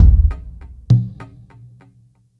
content/hifi-public/sounds/Drums/deepdrum.wav at main
deepdrum.wav